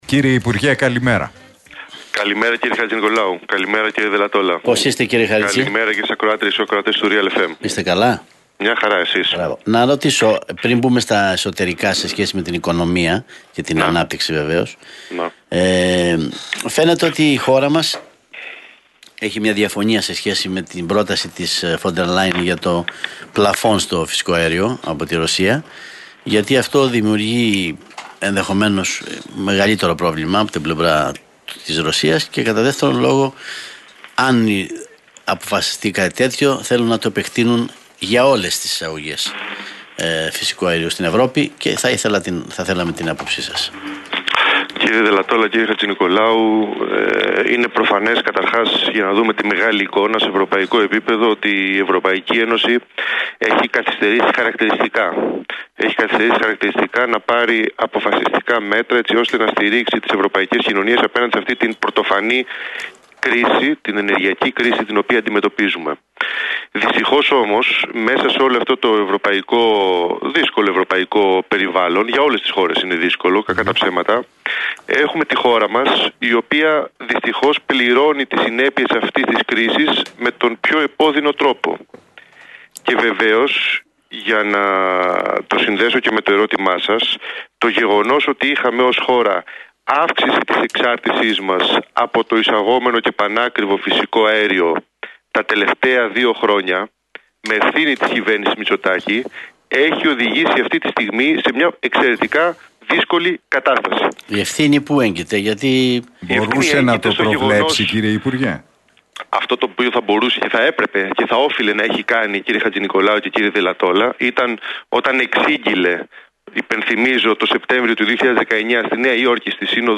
Χαρίτσης στον Realfm 97,8: Η πολιτική Μητσοτάκη οξύνει τις ανισότητες και ανοίγει την ψαλίδα